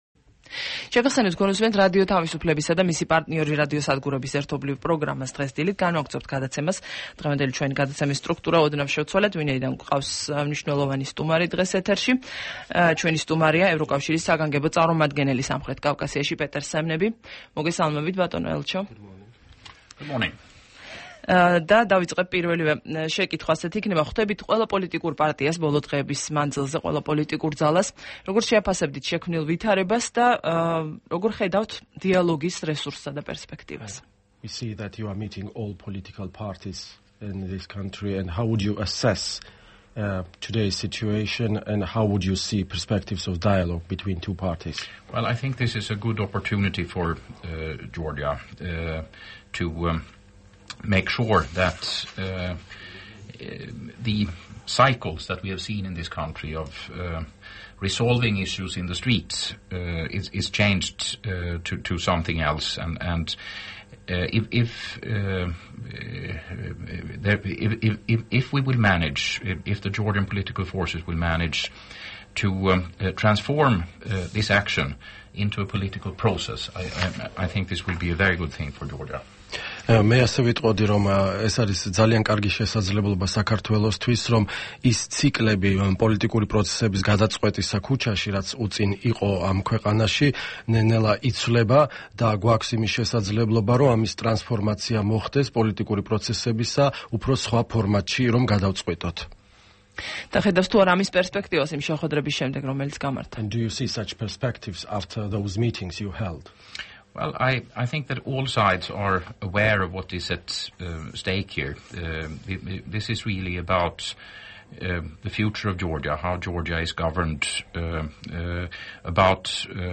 ინტერვიუ პეტერ სემნებისთან